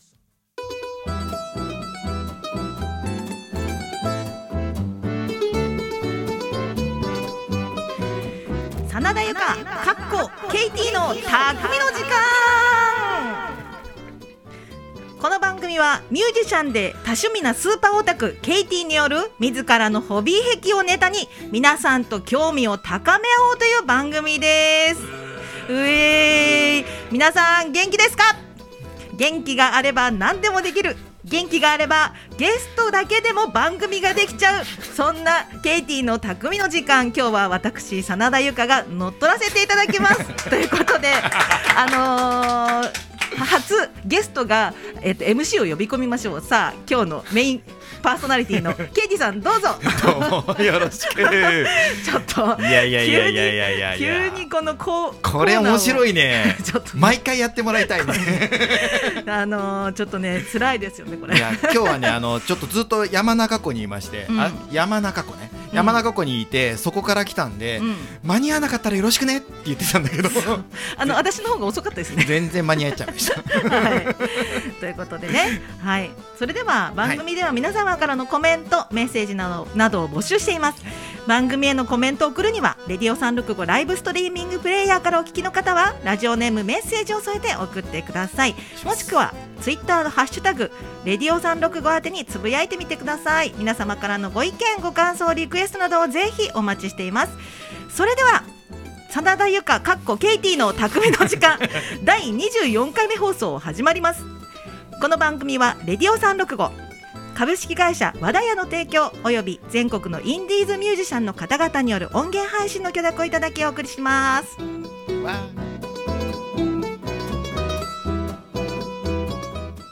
【この音源は生放送のアーカイブ音源となります】